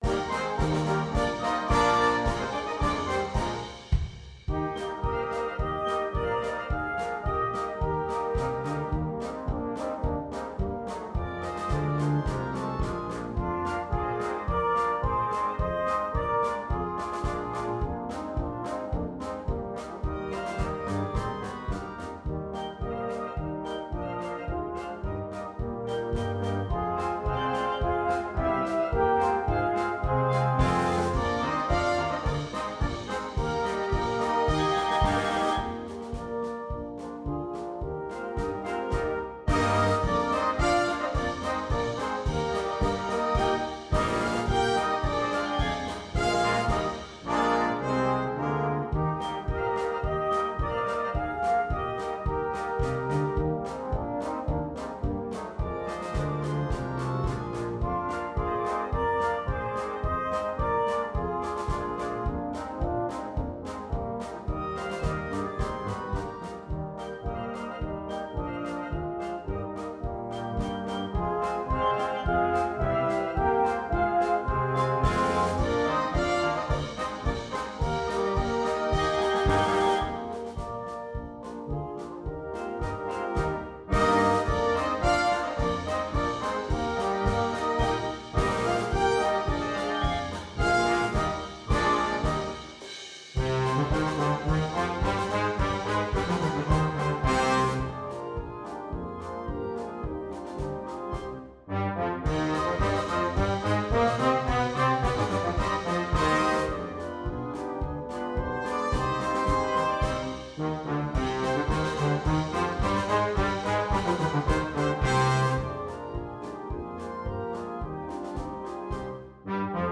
Noten für Blaskapelle